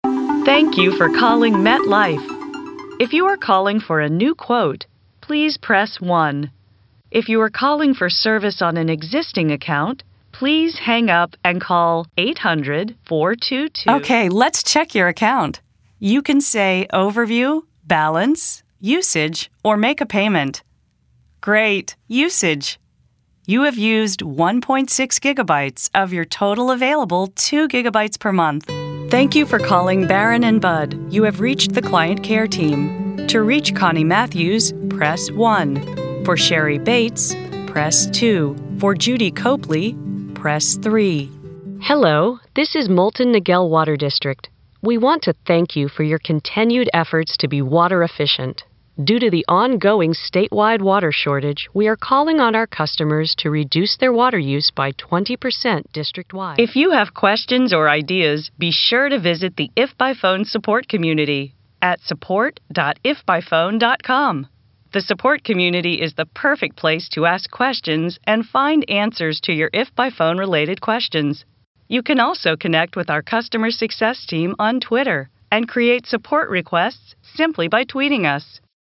Voiceover
Telephony Demo